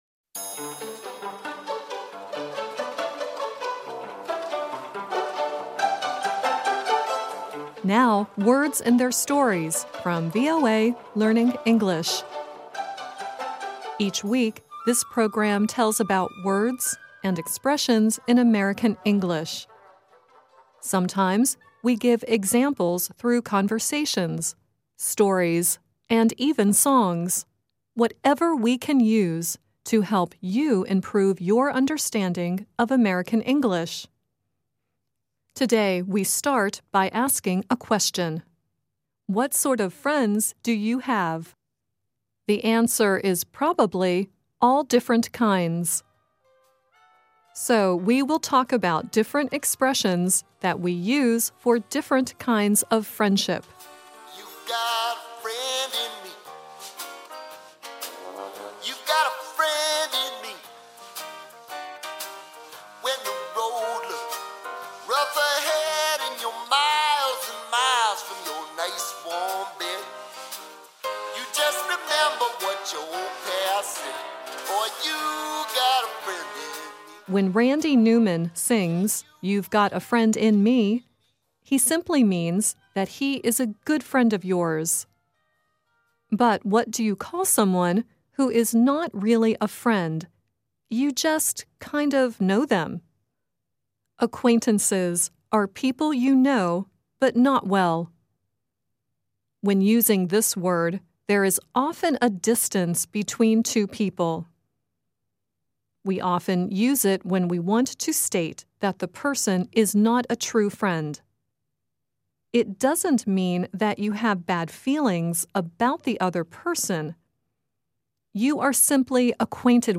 Each week, this program tells about words and expressions in American English. Sometimes we give examples through conversations, stories and even songs -- whatever we can use to help you improve...